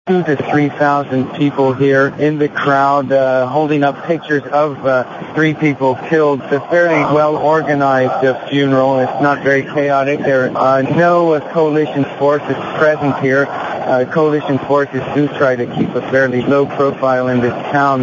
Uroczystości obserwował korespondent CNN